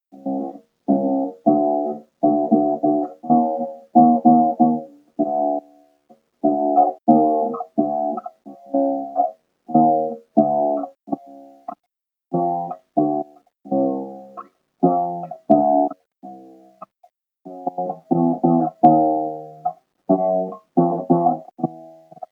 It’s a first instrument now, making sound, it being the fretless three string gourd banjo with a head vaguely evocative of an Asian three toed-sloth appendage.
This evening I worked to get the gourd more unwaveringly affixed to the neck, re-did the bridge to make it lower and lighter, worked the grooves by the tuning pegs to try to lower the angle of attack of the strings to the nut, sanded to 220, applied tung oil, tuned it, such as I can (which is barely at all) and made some sounds
Fretless-3-String-Gourd-1.mp3